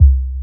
20BASS01  -L.wav